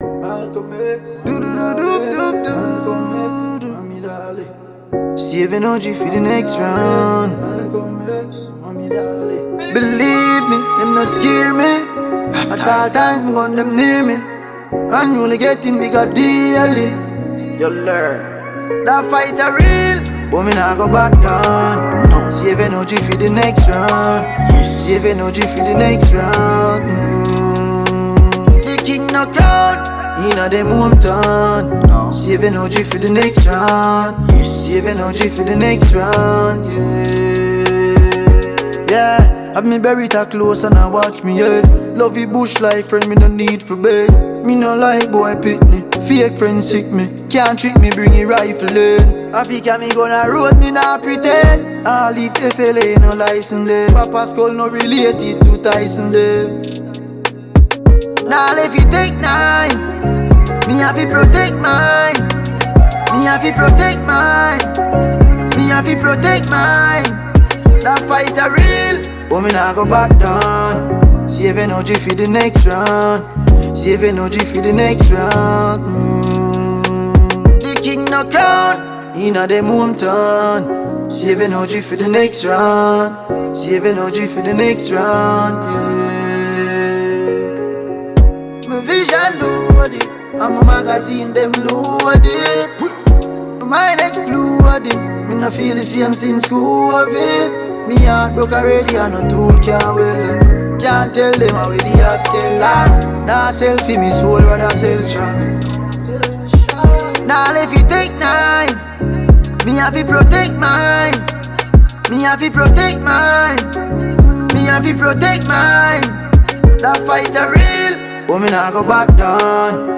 Multiple award-winning Jamaican reggae-dancehall musician